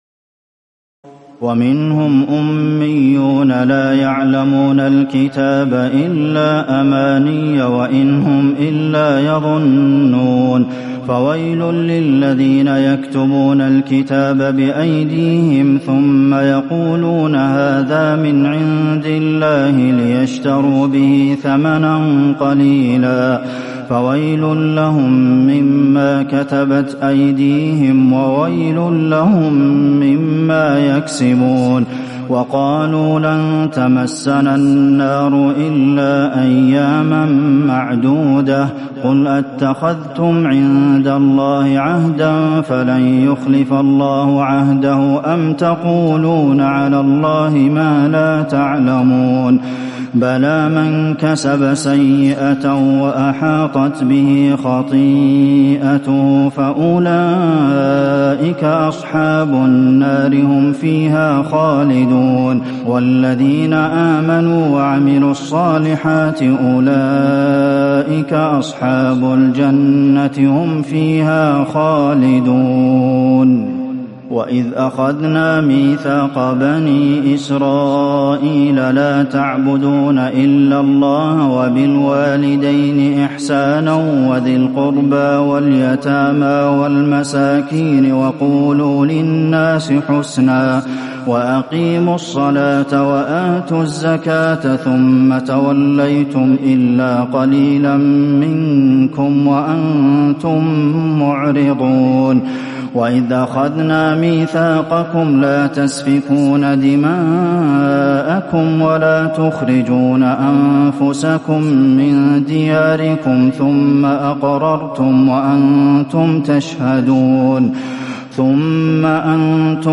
تراويح الليلة الأولى رمضان 1438هـ من سورة البقرة (78-141) Taraweeh 1st night Ramadan 1438H from Surah Al-Baqara > تراويح الحرم النبوي عام 1438 🕌 > التراويح - تلاوات الحرمين